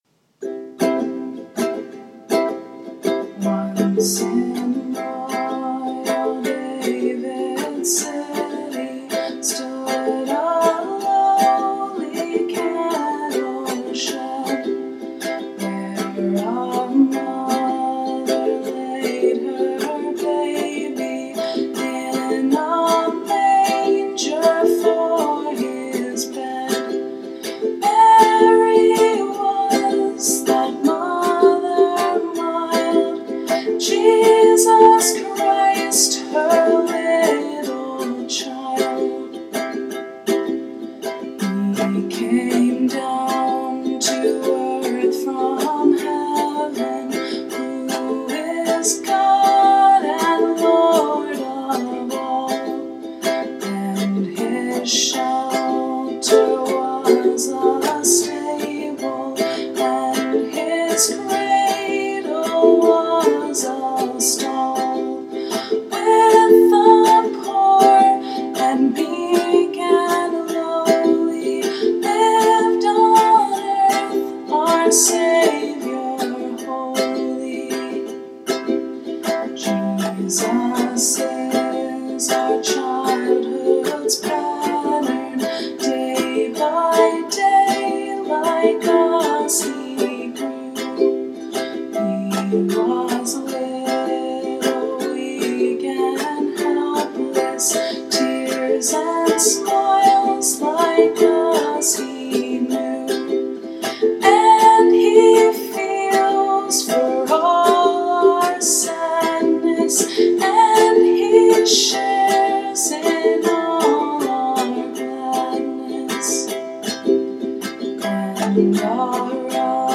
ukulele and voice
Words: Cecil Frances Alexander, 1848
Music: IRBY, Henry John Gauntlett, 1849